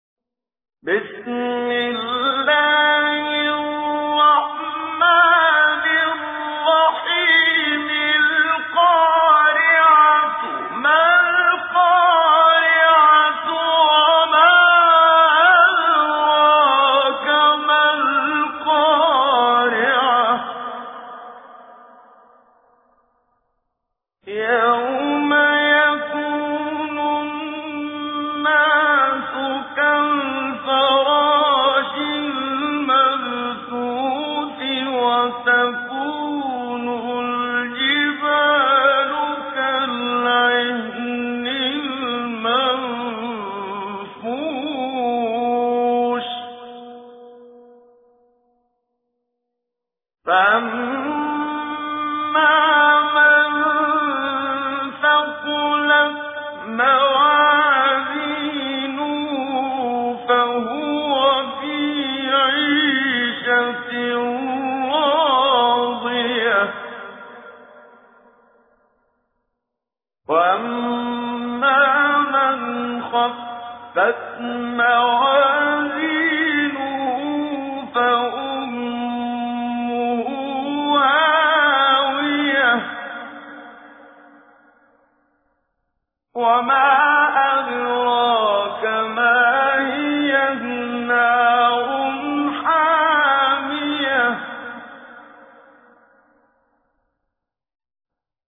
تجويد
سورة القارعة الخطیب: المقريء الشيخ محمد صديق المنشاوي المدة الزمنية: 00:00:00